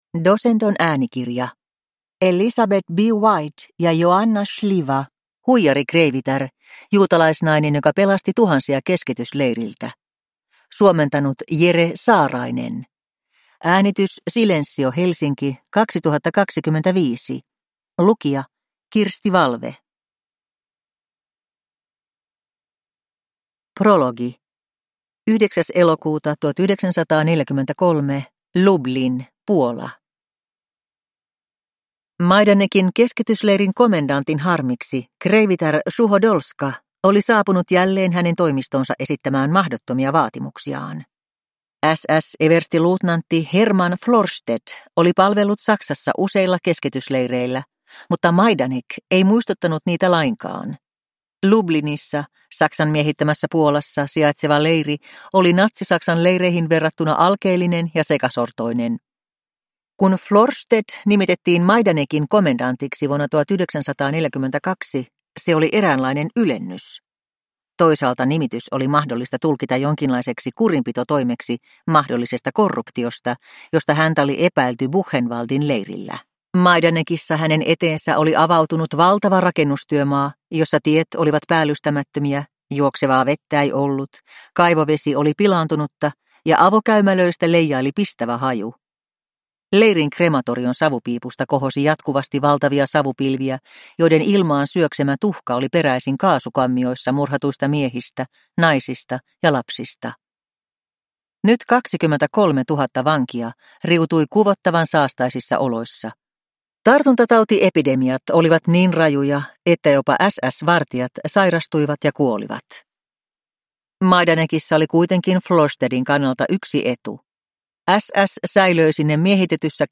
Huijarikreivitär – Ljudbok